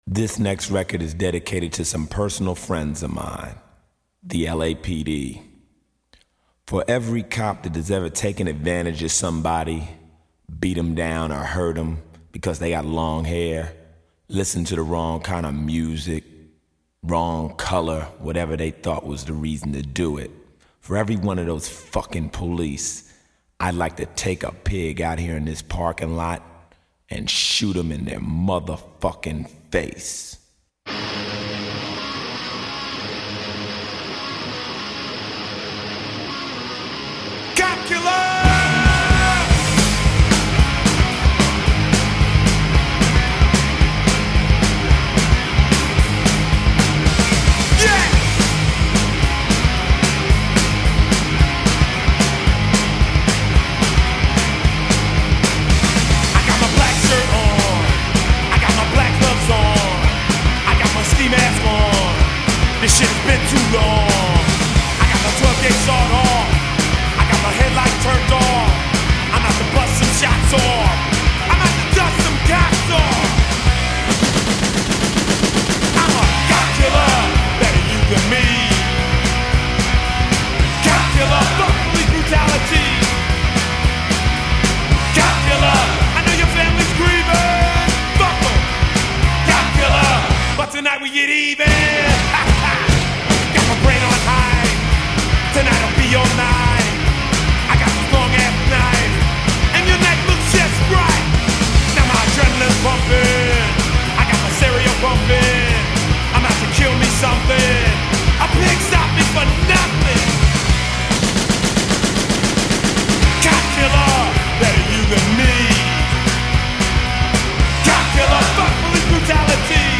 heavy metal band
but one great rock song.